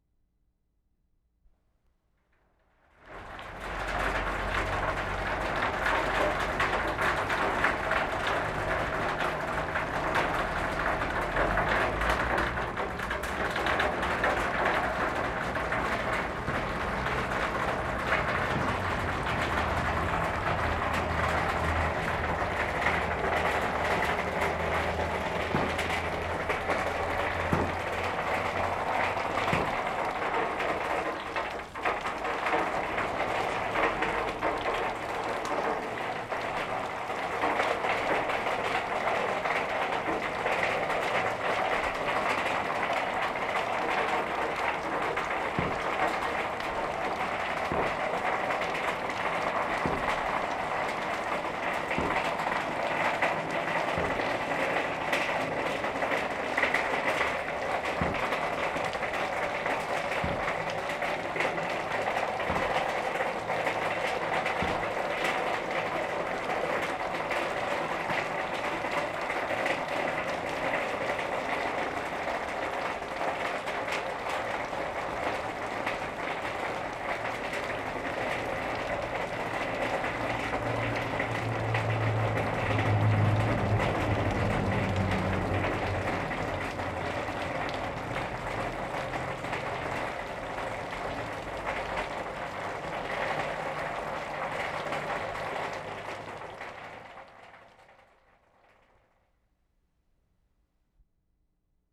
Cembra, Italy April 1,2/75
WATER DRIPPING from eaves to metal roofs.